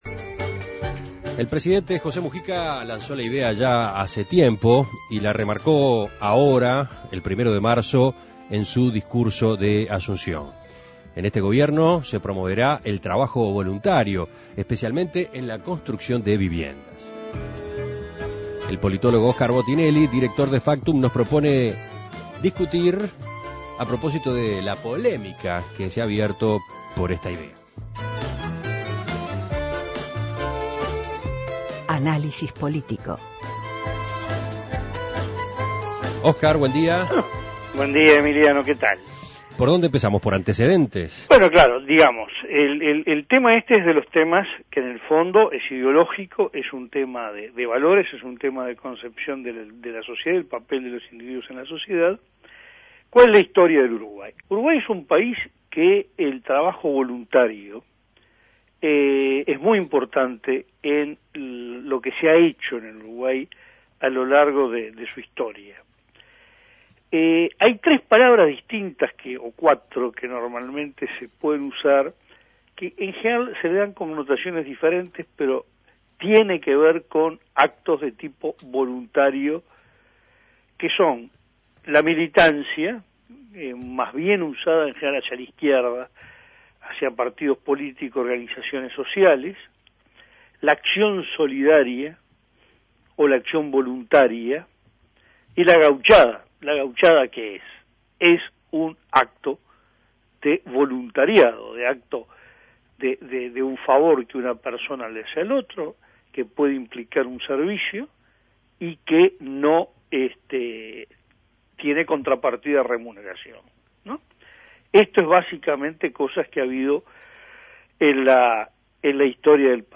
Análisis Político